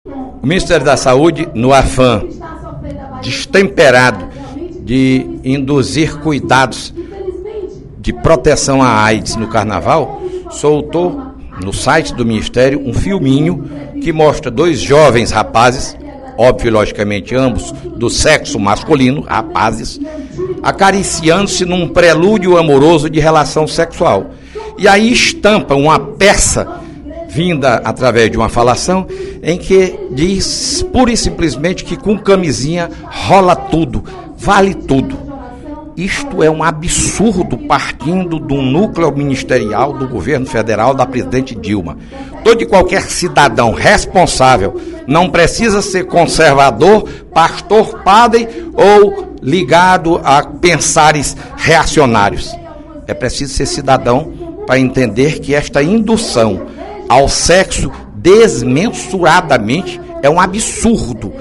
O deputado Fernando Hugo (PSDB) criticou, na manhã desta sexta-feira (10/02), em pronunciamento na Assembleia Legislativa, o filme veiculado no site do Ministério da Saúde que, segundo ele, exibia um casal de rapazes trocando carícias e estimulava o uso da camisinha no período de Carnaval.